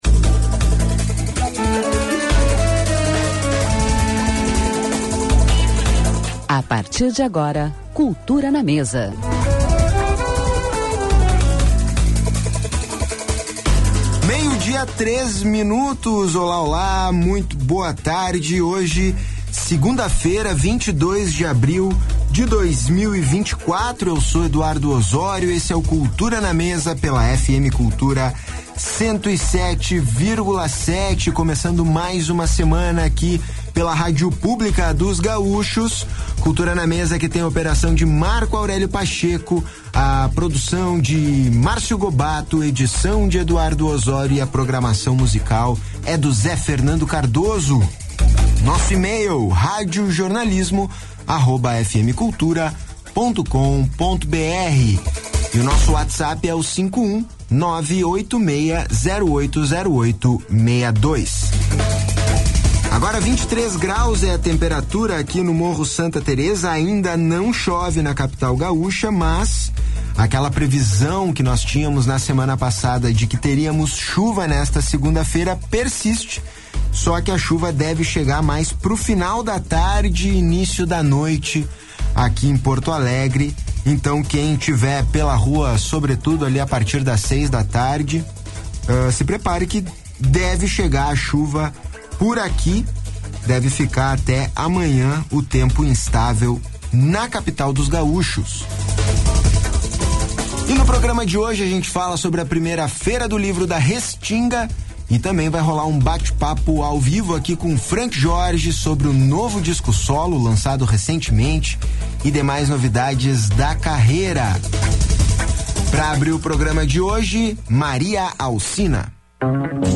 75 anos da Maria Alcina Entrevistas